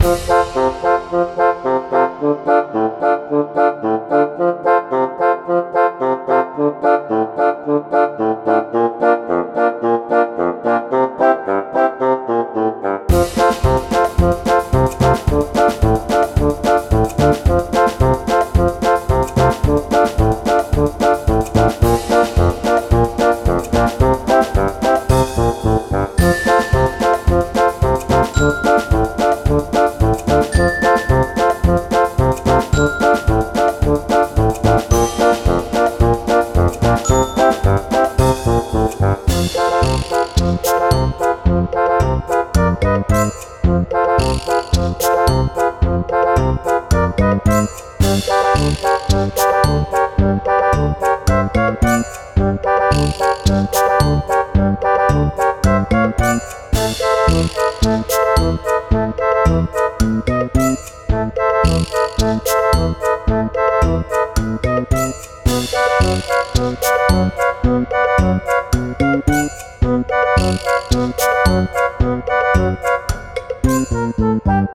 Cute cartoon melody in 2 variations (check it by yourself ;) Loopble